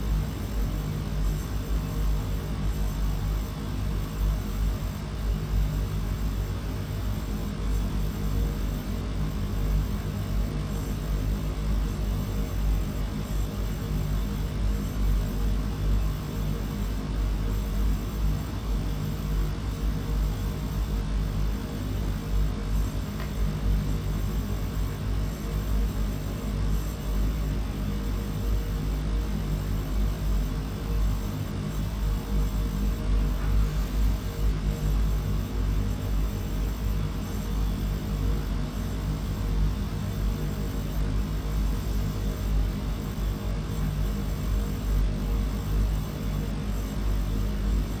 pgs/Assets/Audio/Sci-Fi Sounds/Hum and Ambience/Machine Room Loop 5.wav at master
Machine Room Loop 5.wav